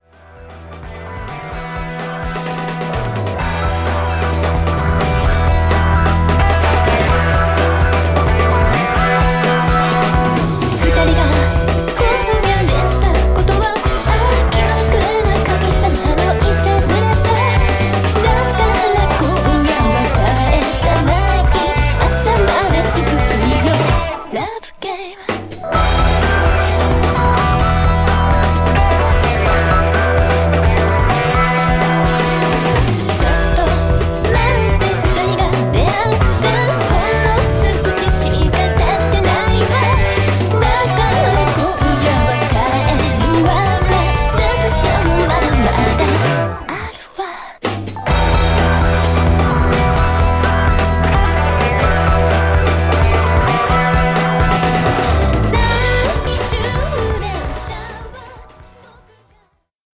ガールズ歌謡ロック・バンド